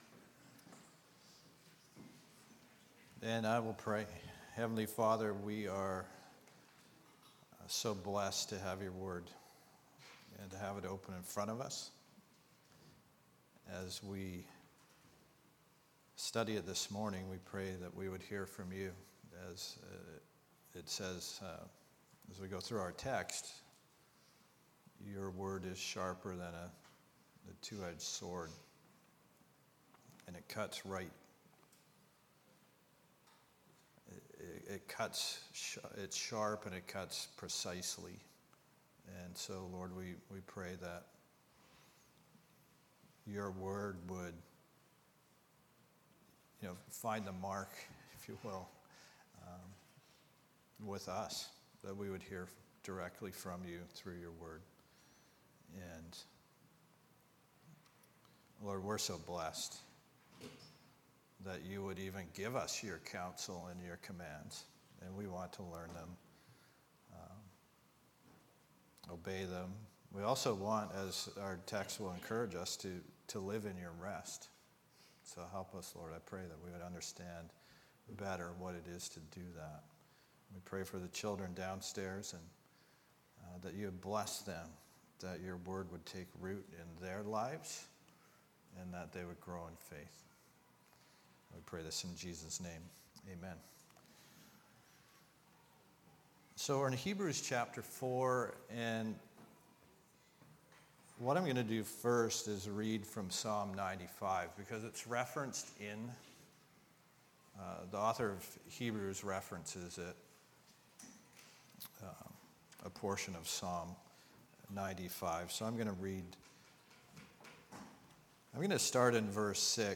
Sermons | Calvary Chapel Comox Valley